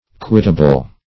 Quittable \Quit"ta*ble\ (kw[i^]t"t[.a]*b'l), a. Capable of being quitted.